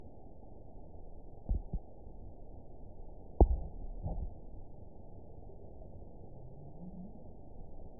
event 921290 date 05/07/24 time 00:49:26 GMT (1 year ago) score 9.04 location TSS-AB04 detected by nrw target species NRW annotations +NRW Spectrogram: Frequency (kHz) vs. Time (s) audio not available .wav